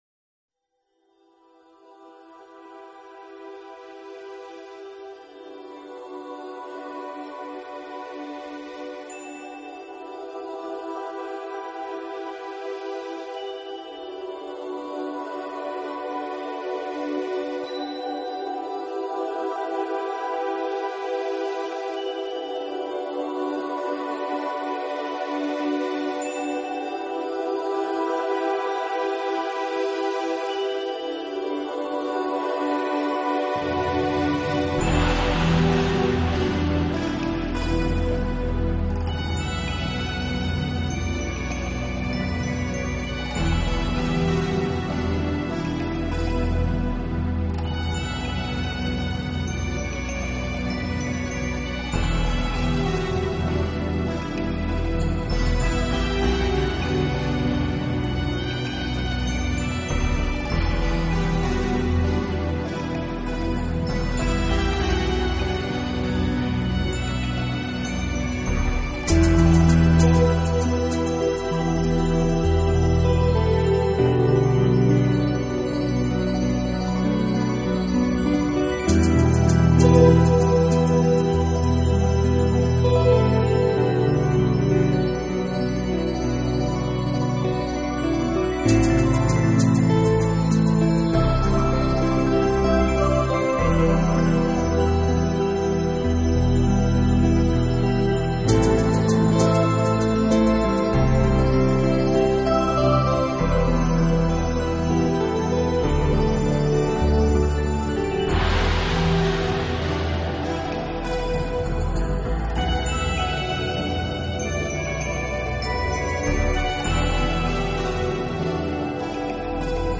and voices layered over hypnotic and provocative percussion.